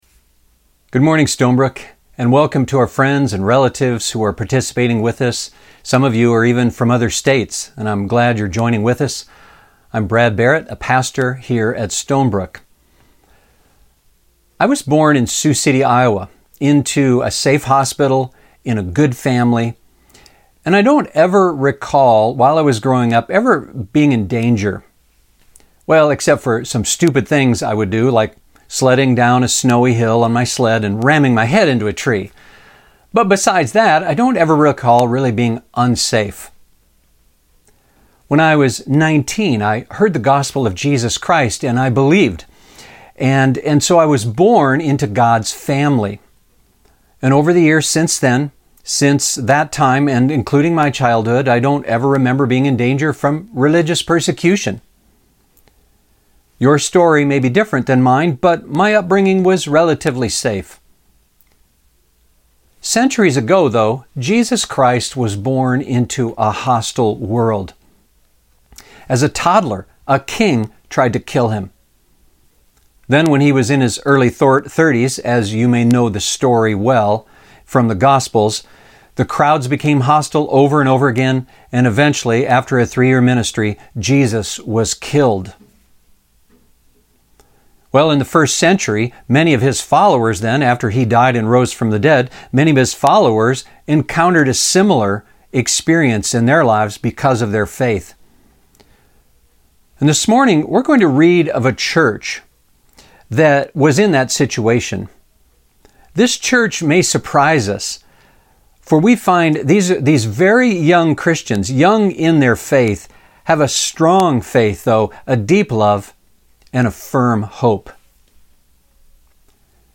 We are beginning a new sermon series going through a letter written by one of God’s most significant spokesmen, a man named Paul.